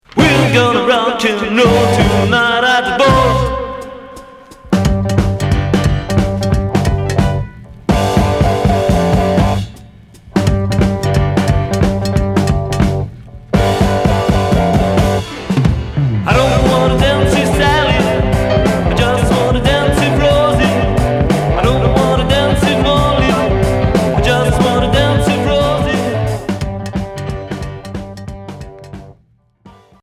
Rock 'n'roll